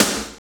Index of /90_sSampleCDs/Roland L-CDX-01/KIT_Drum Kits 5/KIT_Big Funk Kit
SNR SPLAT03R.wav